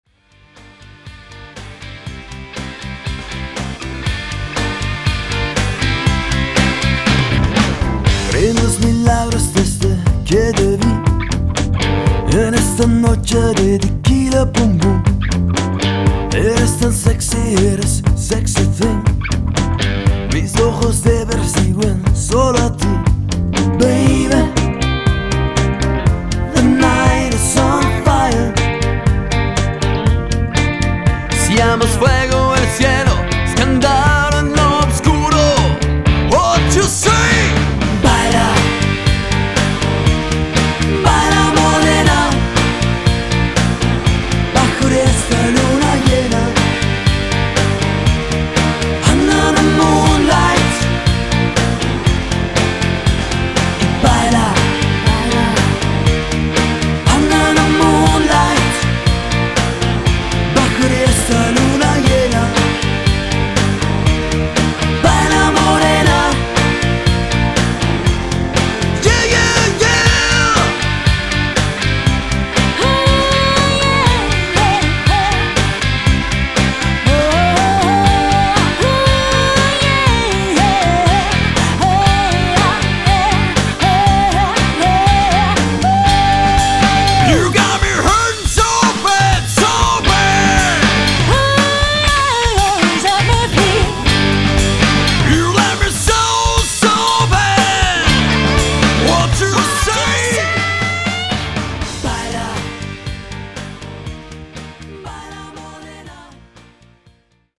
Trio oder Quartett – Sängerin u. Sänger